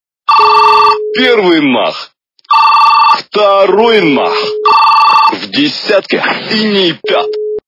» Звуки » Люди фразы » Голос - Первый мах, Второй мах В десятке
При прослушивании Голос - Первый мах, Второй мах В десятке качество понижено и присутствуют гудки.